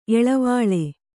♪ eḷavāḷe